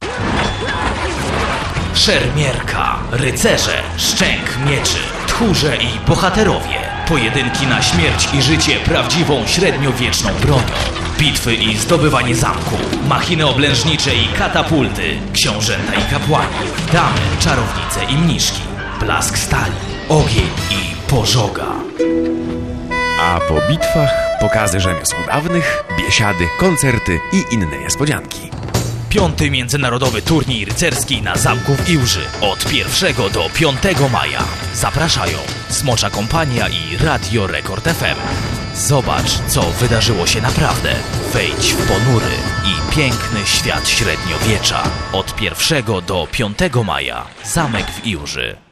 Spot radiowy